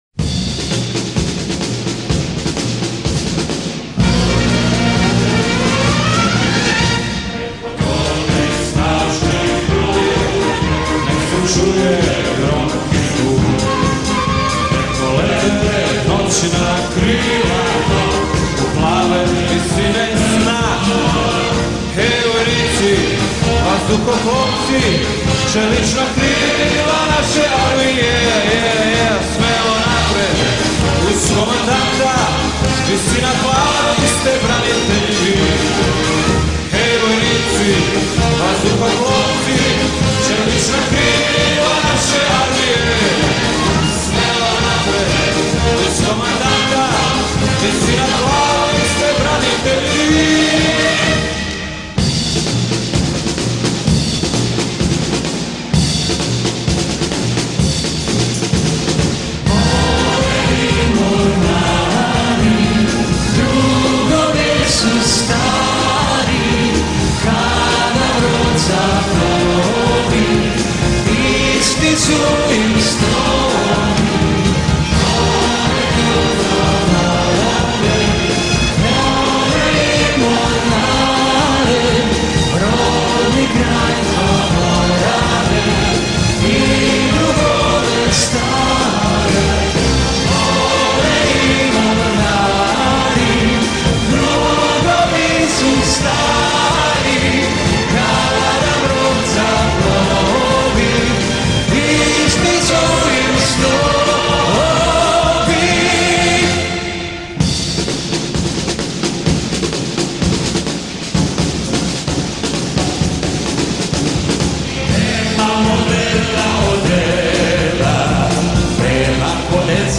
공군, 해군 및 지상군을 위한 행진곡 메들리